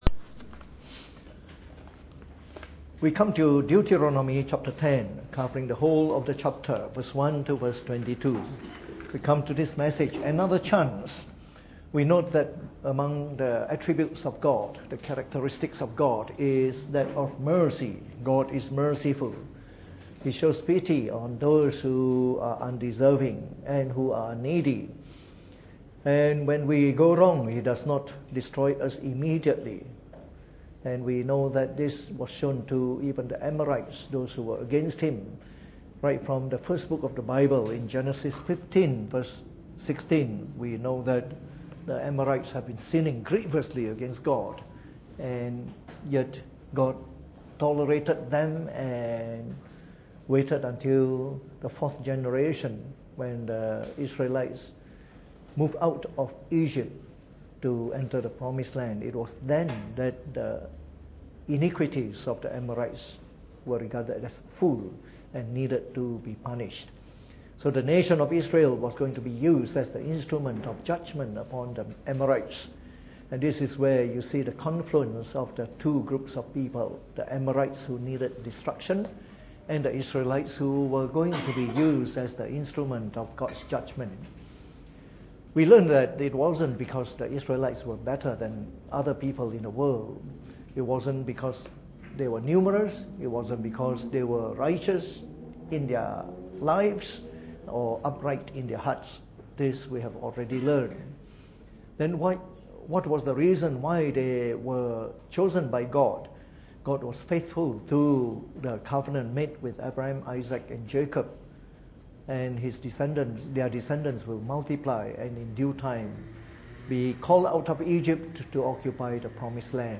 Preached on the 21st of March 2018 during the Bible Study, from our series on the book of Deuteronomy.